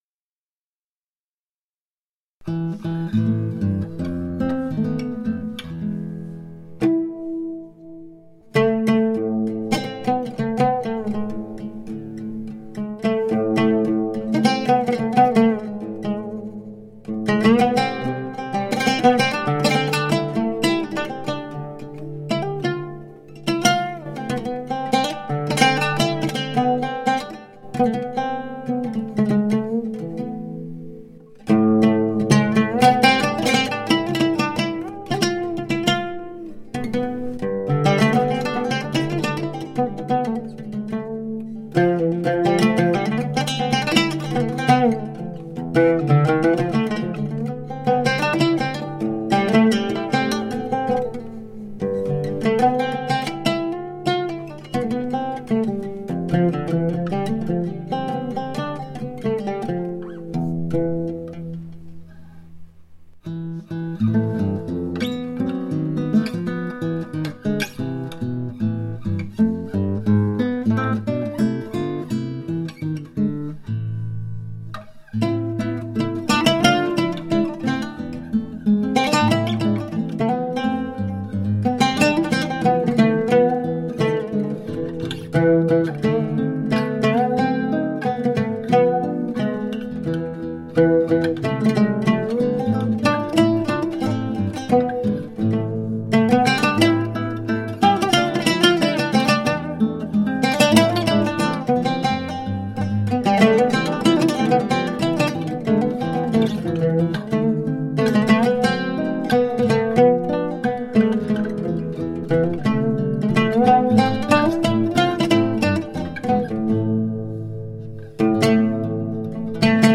Recreating traditional music from a modern perspective.
Classical
Impressionism
Instrumental
Classical Guitar, Oud, Santur